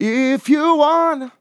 Categories: Vocals Tags: dry, english, If, LOFI VIBES, LYRICS, male, sample, wanna, you
MAN-LYRICS-FILLS-120bpm-Am-25.wav